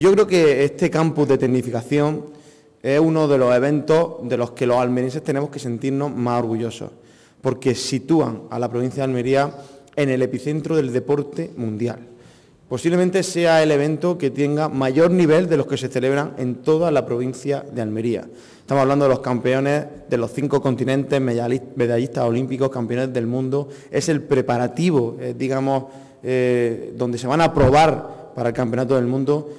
26-06_esgrima_diputado.mp3